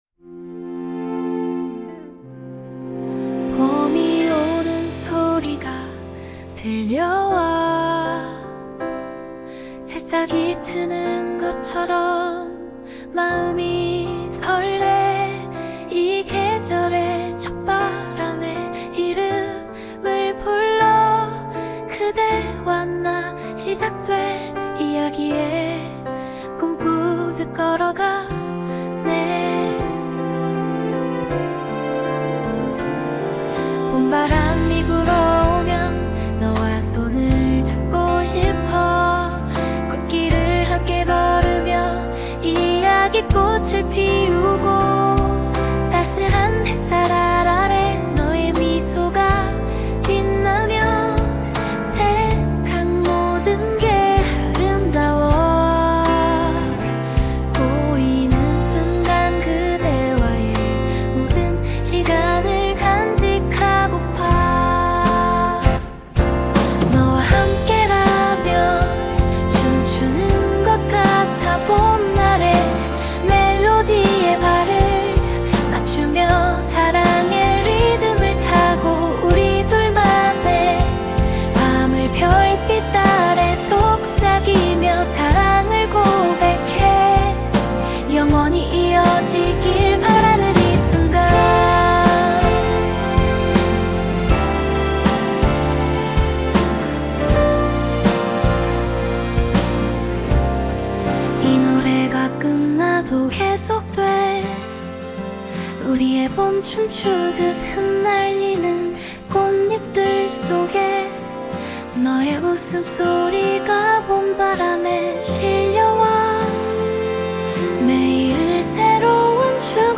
다음 음악은 제가 Udio로 생성한 음악입니다.